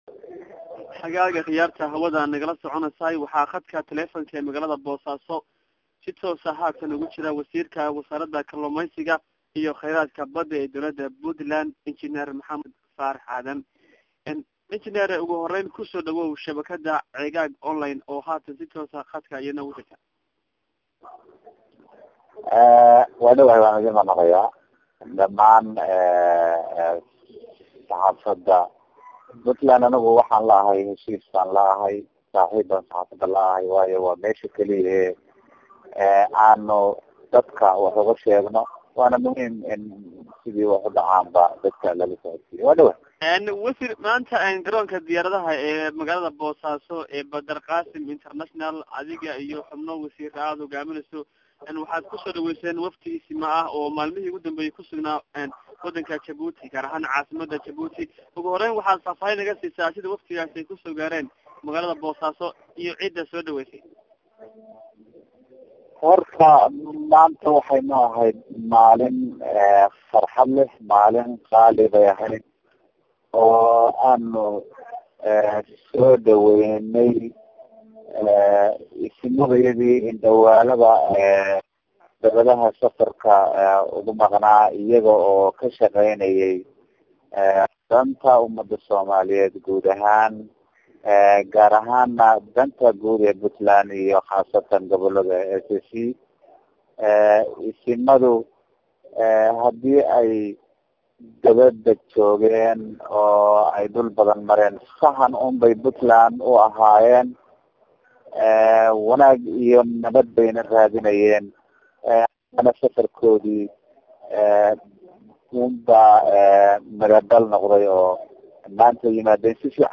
Issimadii ka qeybgalay Shirkii Nairobi oo kusoo guryo noqday Puntland oo aanu ka wareysanay Wasiirka Kaluumeysiga